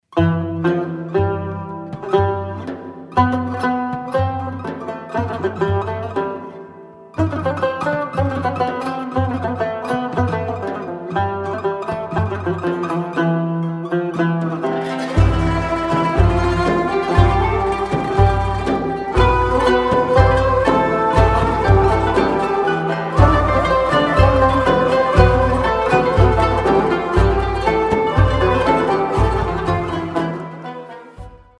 رینگتون زیبا و بی کلام (عاشقانه و محزون)
(ملودی استانبولی)